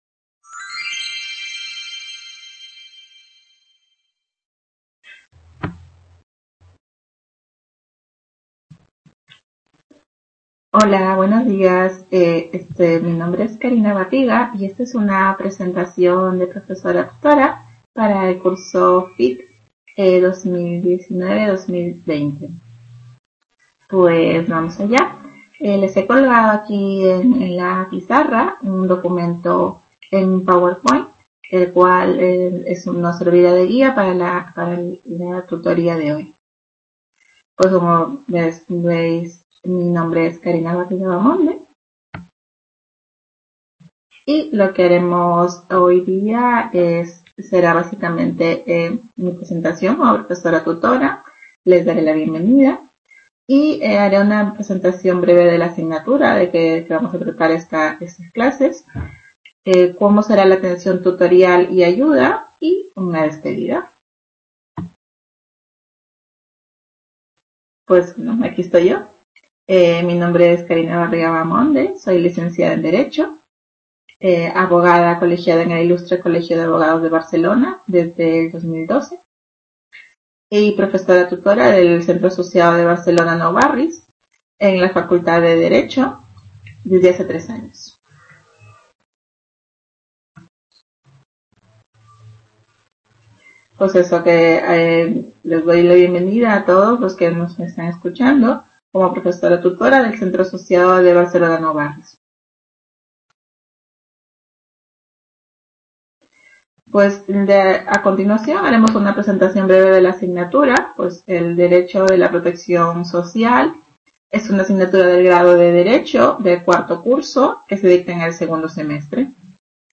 Webconferencia
Video Clase
Tutoría (Enseñanza)